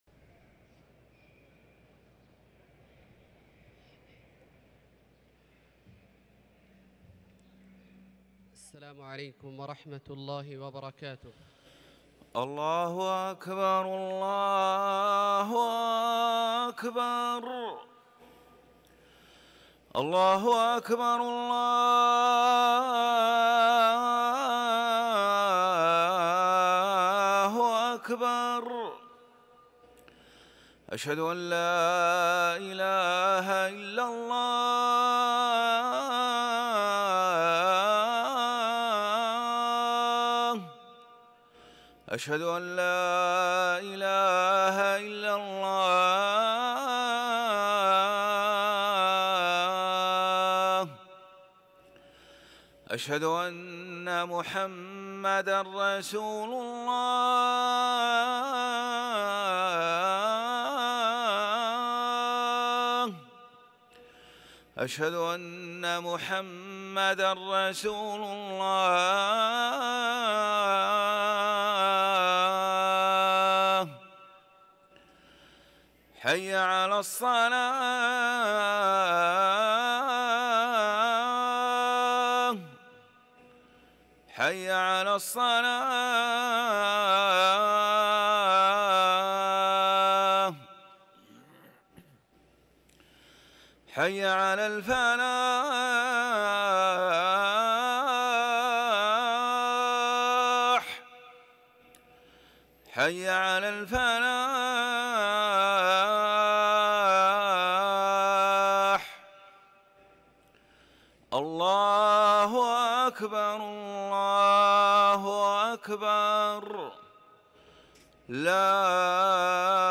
اذان الجمعة الثاني